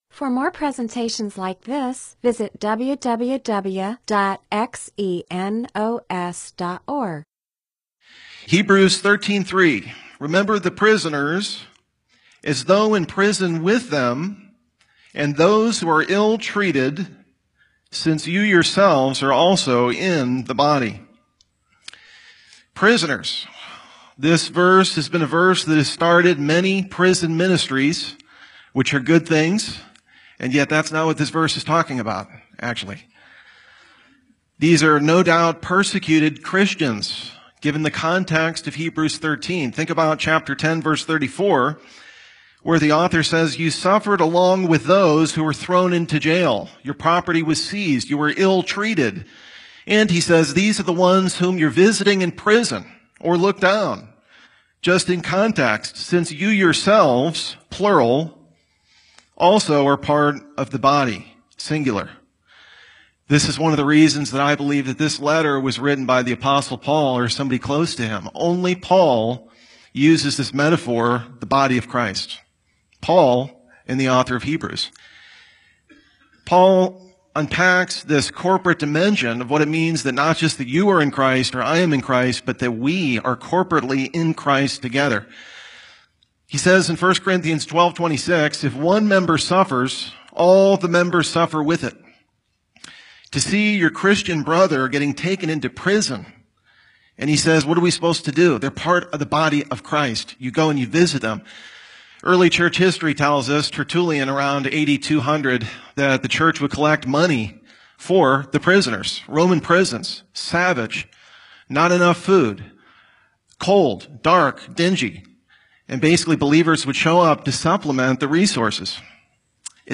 MP4/M4A audio recording of a Bible teaching/sermon/presentation about Hebrews 13:3; John 15:18-20; Luke 6:22; 1 Timothy 1:8.